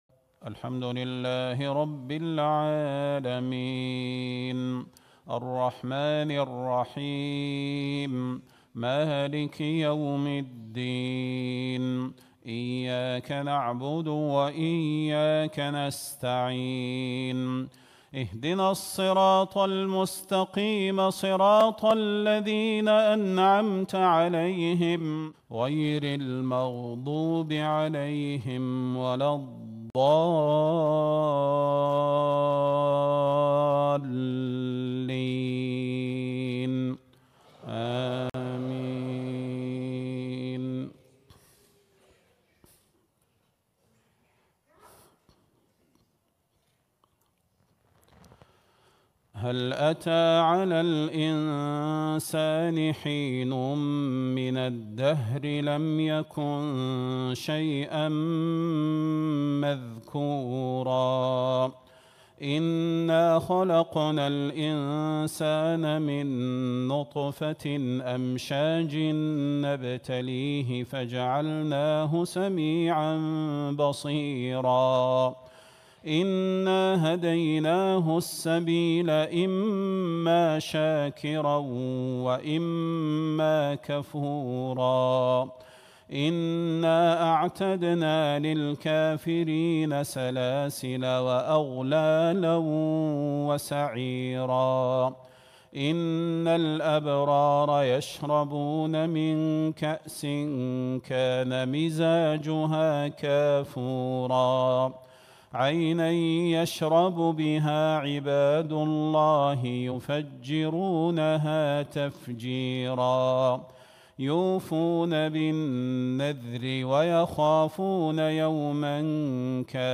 صلاة المغرب ٢٥ شوال ١٤٤٦ في جامع الملك سلمان -حفظه الله- في جزر المالديف. > تلاوات و جهود الشيخ صلاح البدير > تلاوات وجهود أئمة الحرم النبوي خارج الحرم > المزيد - تلاوات الحرمين